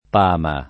[ p # ma ]